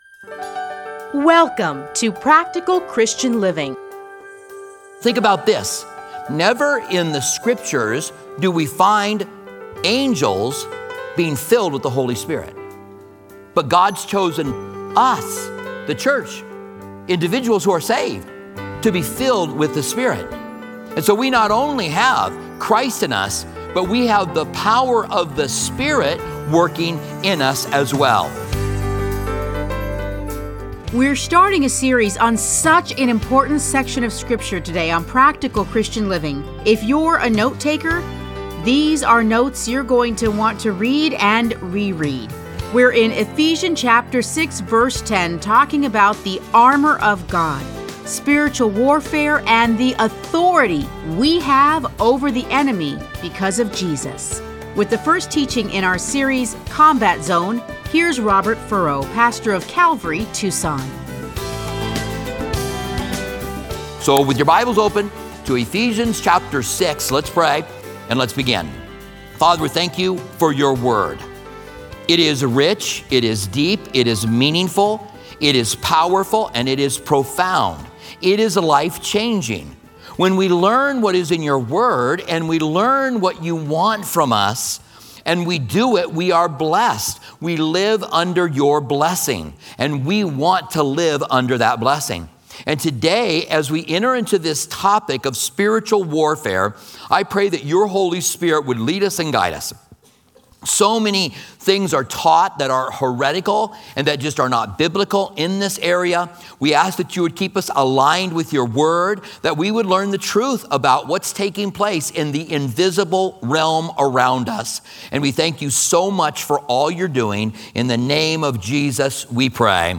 Listen to a teaching from Ephesians 6:10.